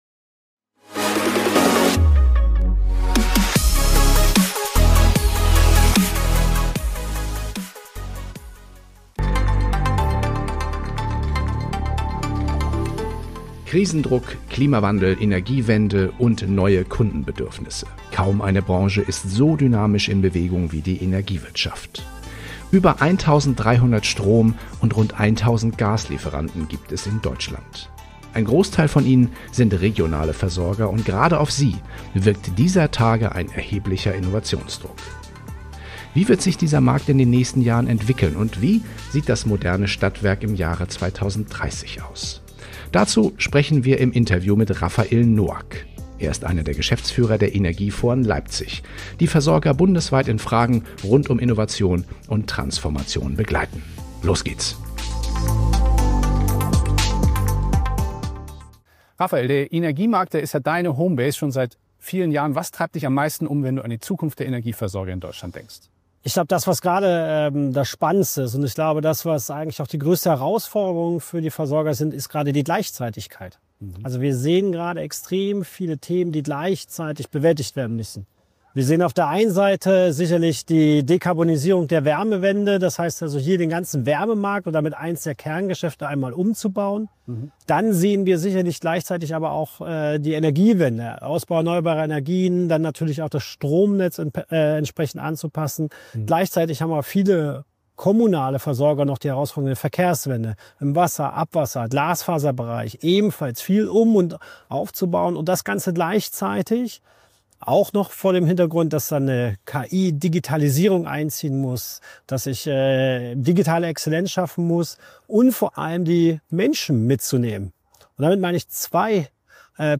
Ein inspirierendes Gespräch über Kulturwandel, Technologie und Zusammenarbeit, und darüber, wie KI den Energiesektor neu formt.